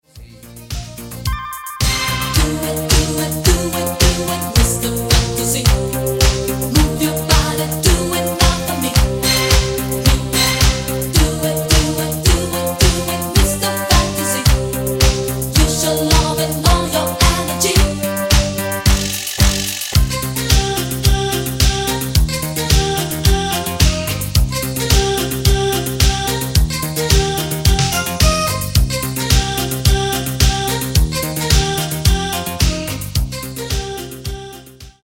Genere:   Disco Elettronica